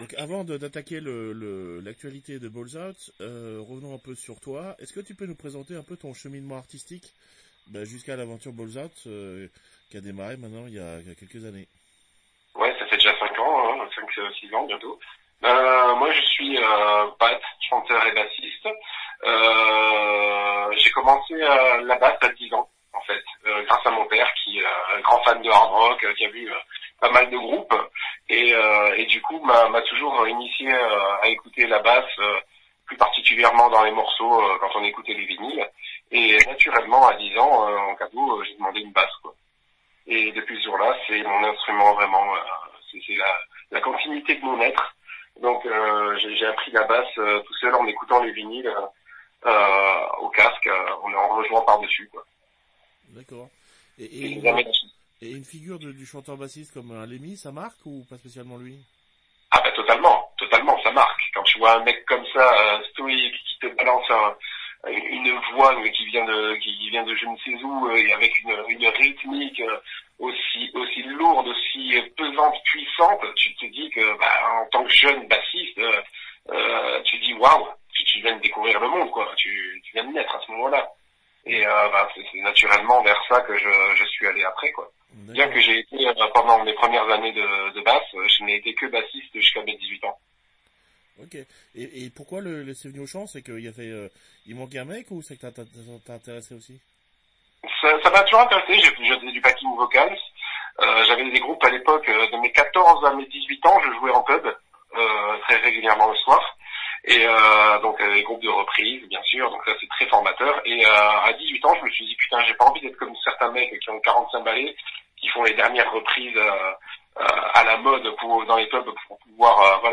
BALLS OUT (Interview